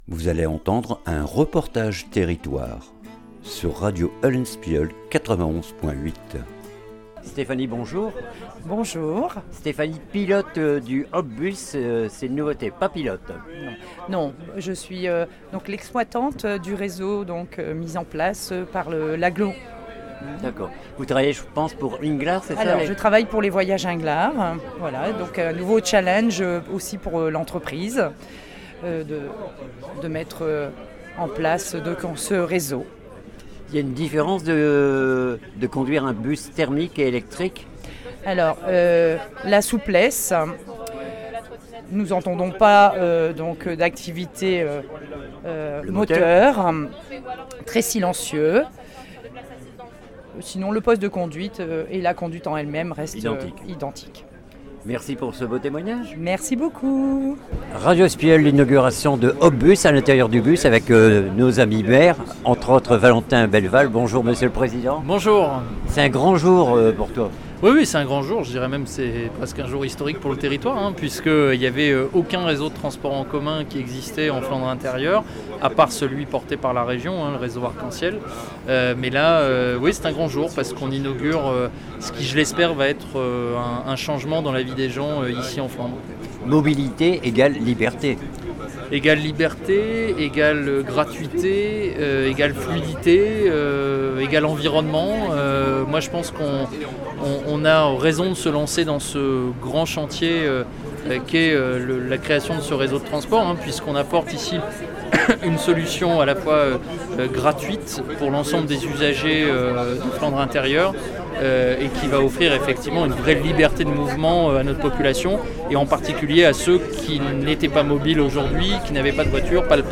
REPORTAGE TERRITOIRE HOP BUS COEUR DE FLANDRE AGGLO